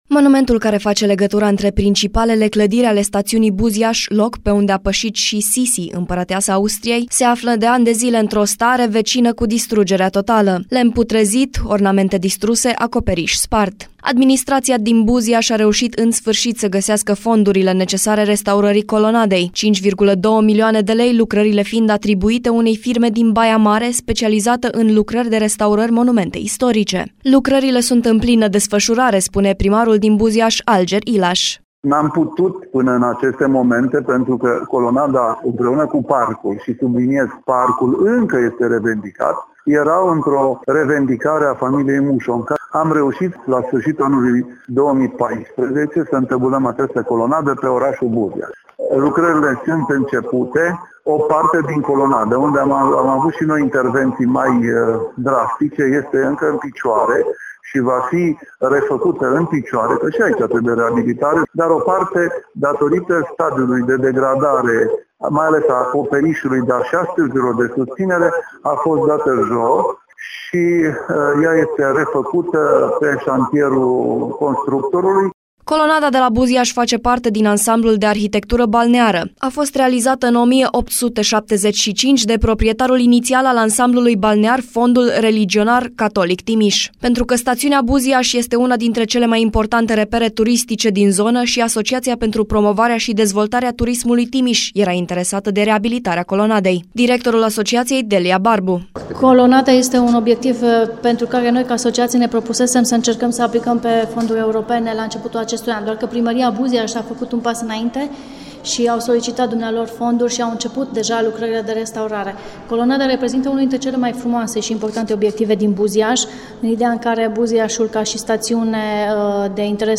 Primarul din Buziaş, Alger Ilaş își amintește de aspectul colonadei din copilăria lui.